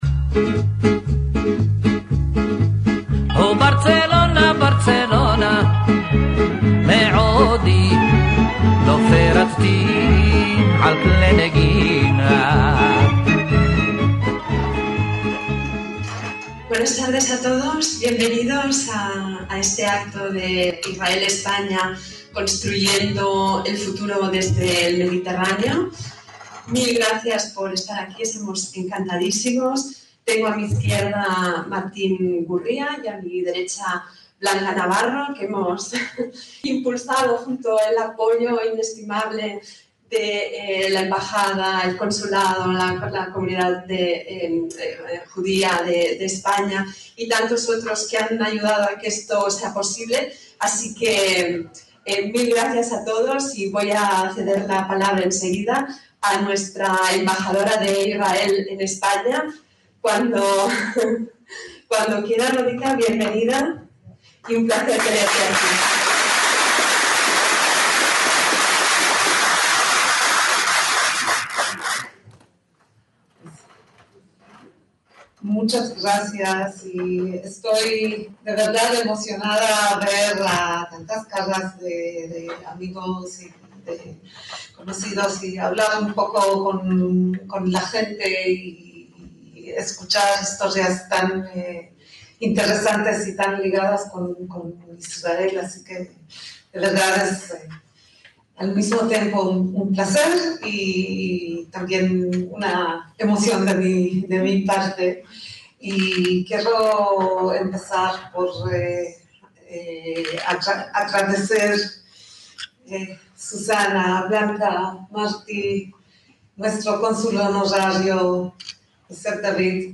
ACTOS EN DIRECTO - ISFA (Israel-Spain Forum Alliance) organizó el 24 de noviembre de 2021 en Barcelona un evento con tres mesas de diálogo para dar a conocer un manifiesto que defiende un acuerdo de amistad y cooperación entre la capital catalana y la ciudad israelí de Tel-Aviv.